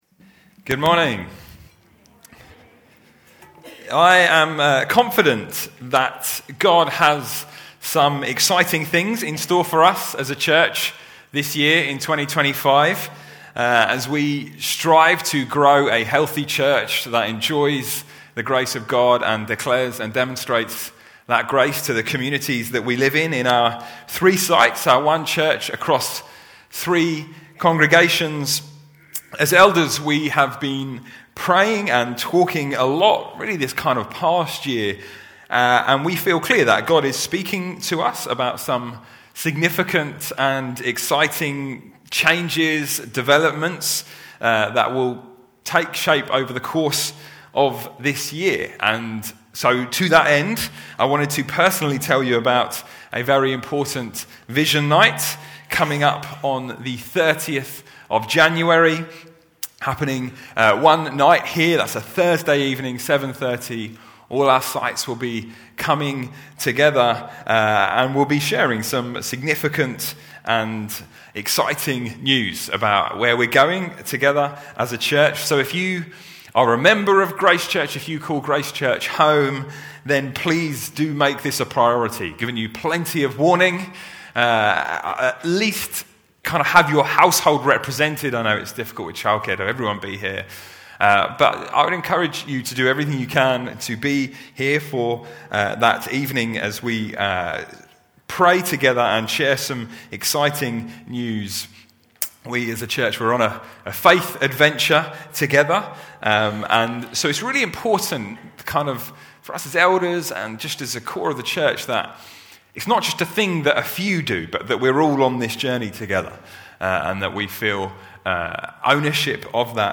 Other Sermons 2025